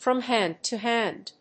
アクセントfrom hánd to hánd